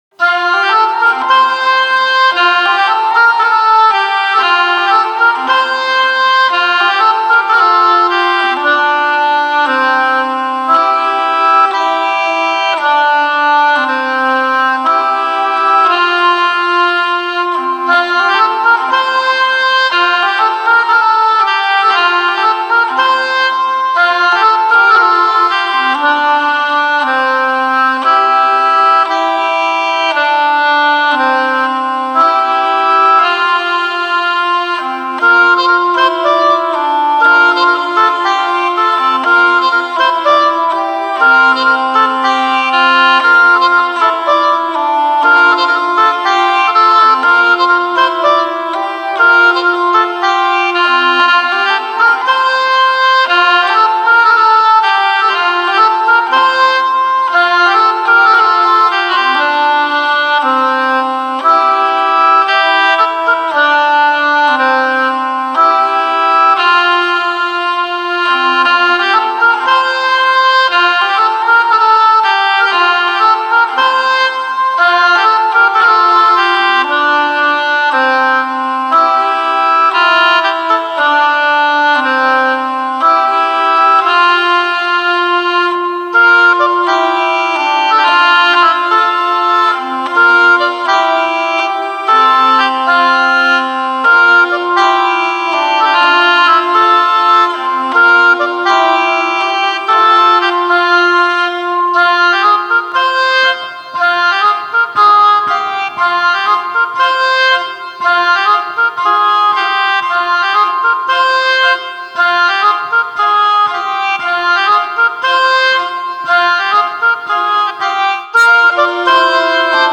avec une association improbable
Contrôleur midi et vielle à roue